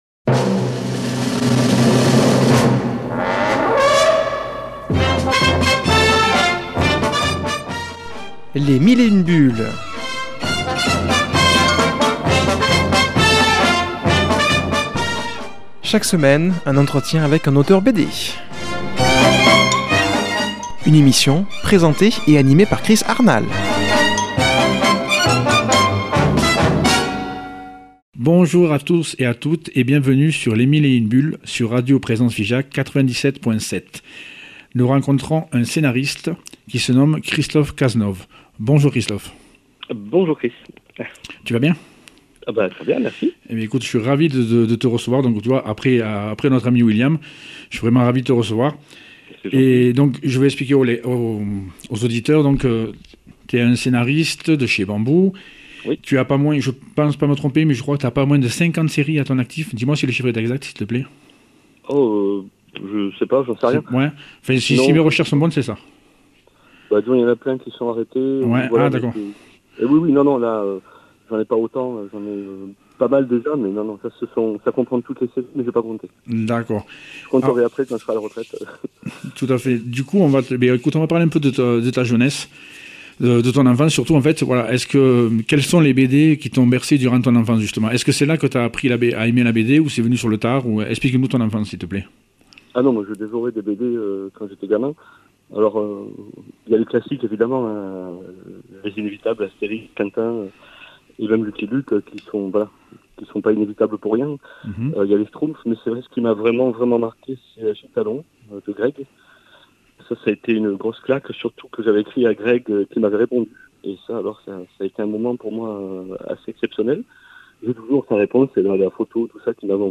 qui a comme invitée au téléphone